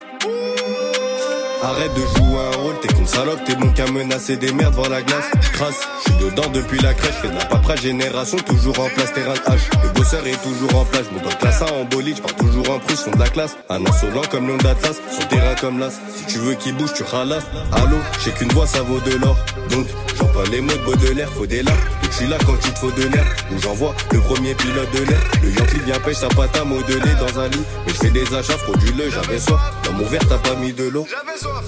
Rap / Hip Hop